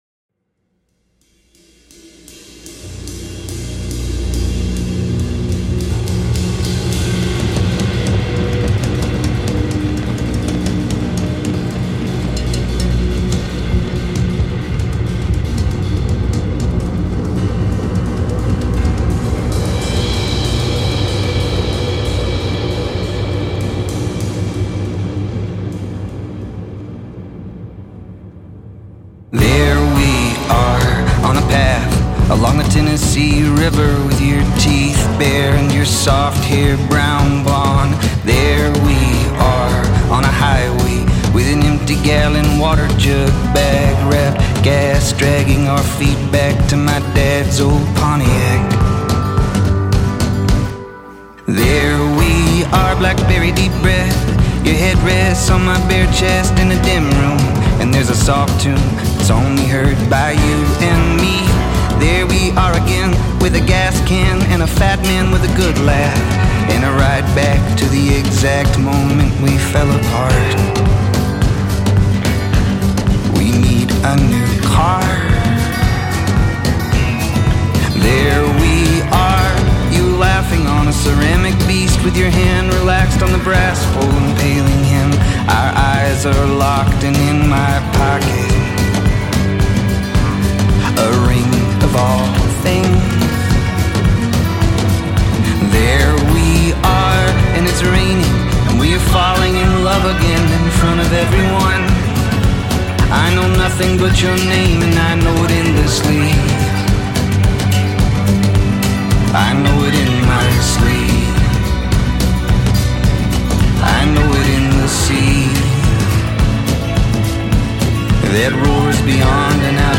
Country / Folk / Americana / Blues